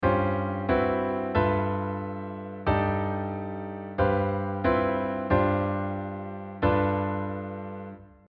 2 – To Create Harmonic Tension Over a Pedal Note
In this example, the first bar is a real parallel chord motion example while the second bar is tonal